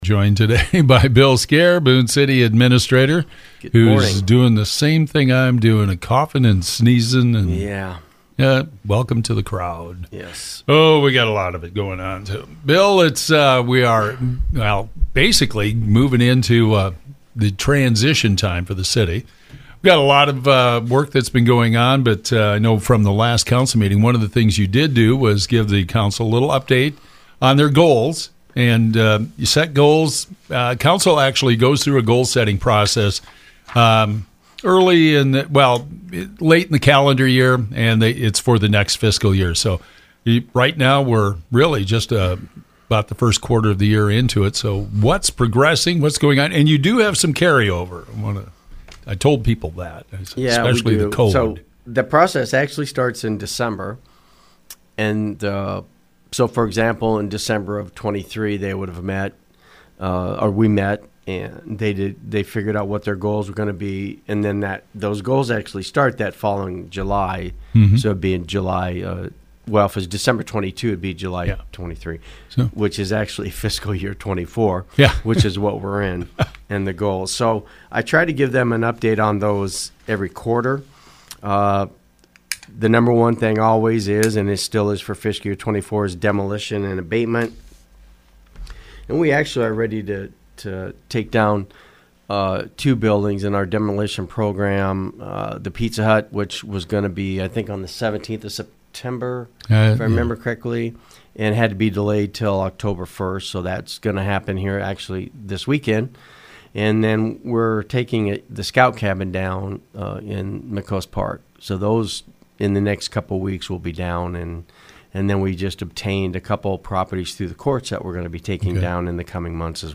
Bill Skare, Boone City Administrator, talks about projects underway in the City of Boone. Hancock to Corporal Snedden Drive is open with some final work being done. Paving is expected to begin soon on South Marion and West Park. Fire Department is planning a controlled burn on the former Pizza Hut building this weekend.